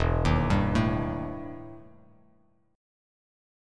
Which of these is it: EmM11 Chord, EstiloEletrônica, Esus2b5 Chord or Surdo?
EmM11 Chord